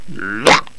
daemon_attack3.wav